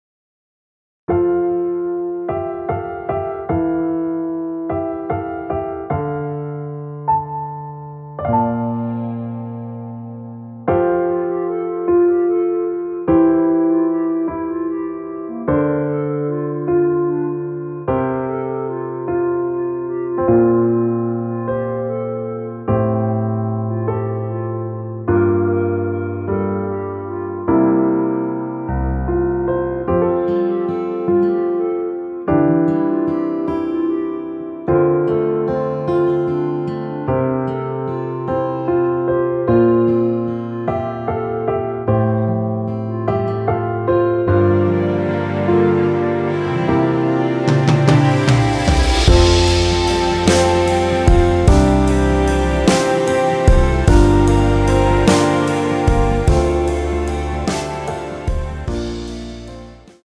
멜로디 MR이란
멜로디 MR이라고 합니다.
앞부분30초, 뒷부분30초씩 편집해서 올려 드리고 있습니다.